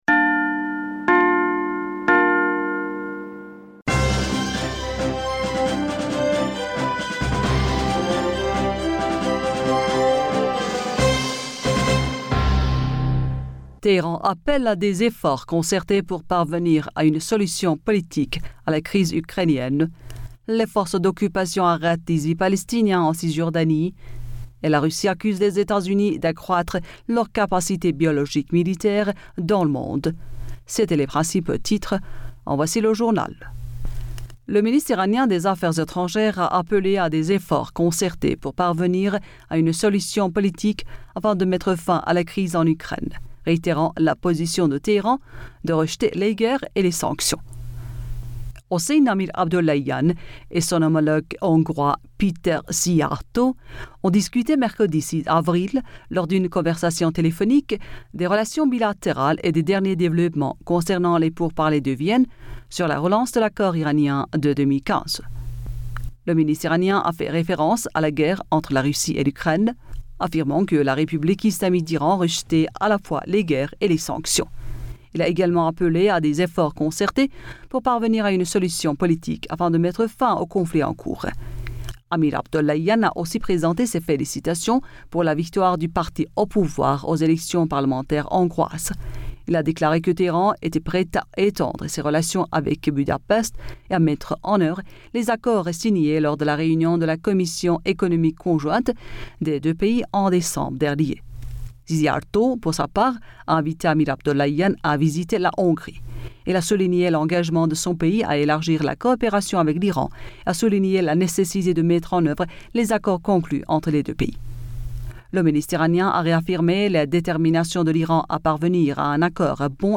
Bulletin d'information Du 07 Avril 2022